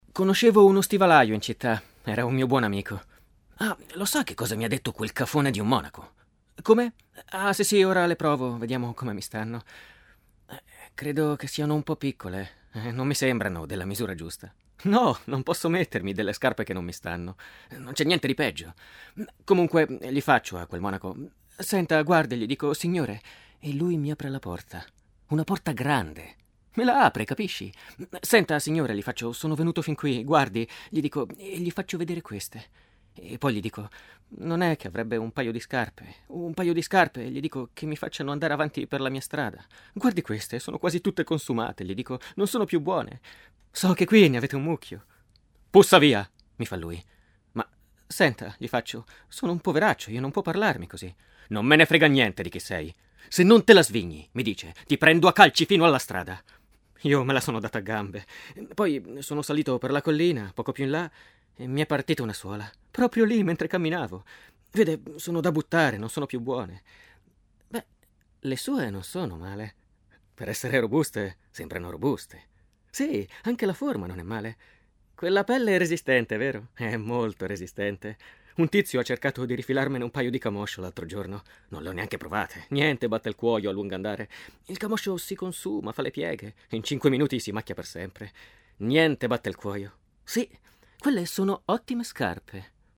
attore doppiatore con esperienza di teatro, cinema, tv, doppiaggio
Kein Dialekt
Sprechprobe: Sonstiges (Muttersprache):